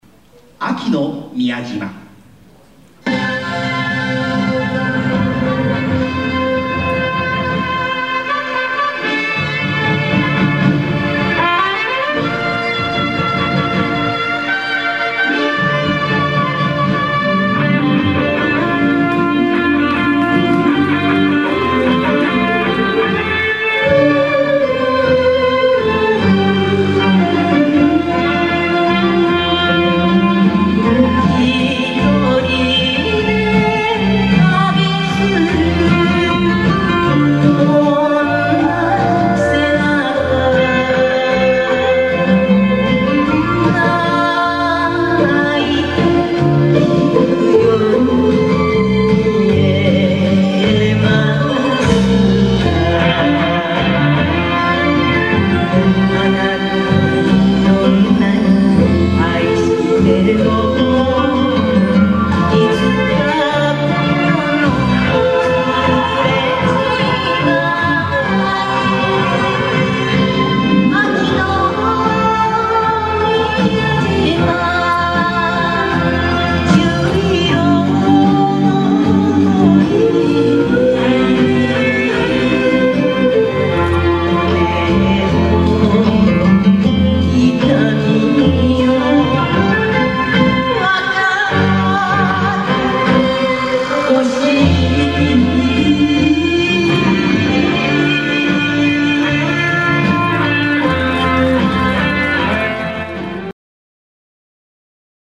第１3回教室発表会